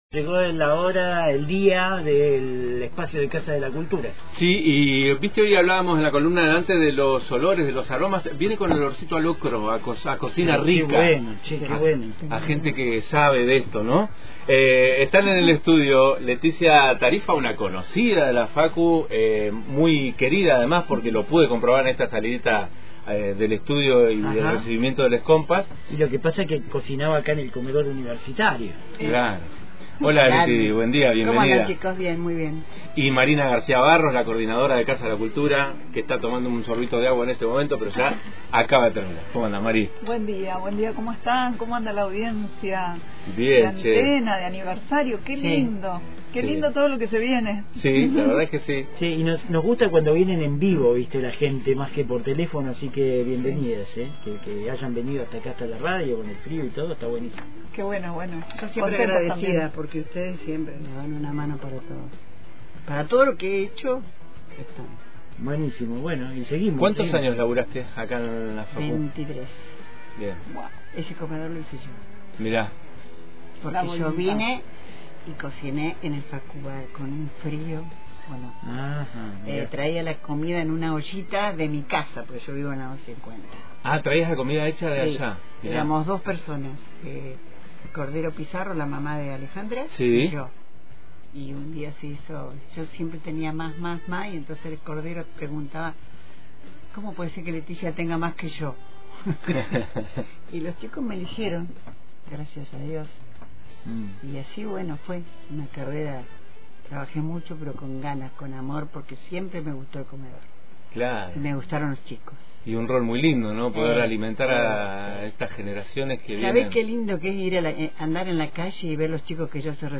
nos visitaron en el Estudio «Madres de Plaza de Mayo» para contarnos de esta cocinada que se viene para el sábado.